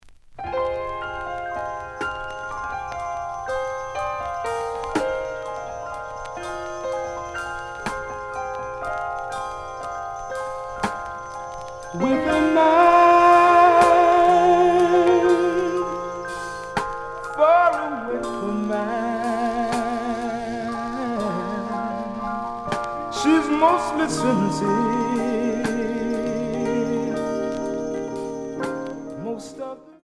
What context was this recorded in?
The audio sample is recorded from the actual item. Some periodic noise on middle of A side.